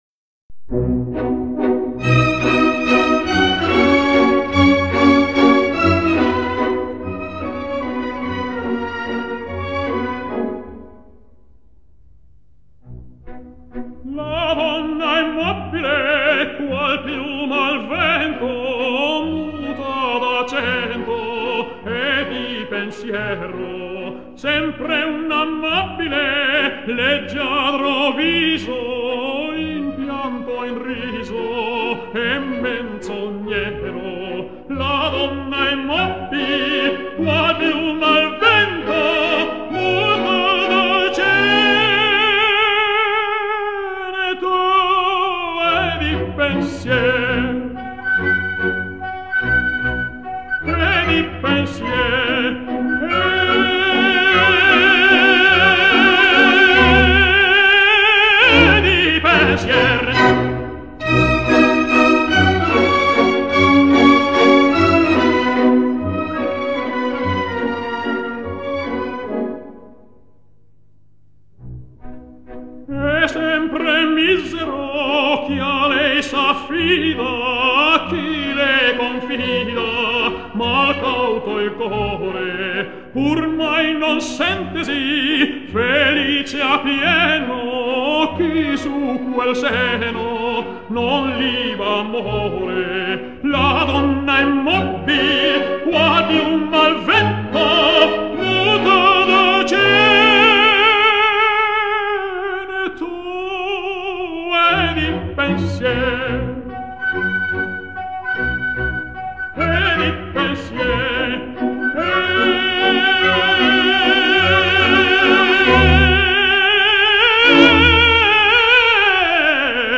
男高音
第三幕曼图雅公爵的咏叹调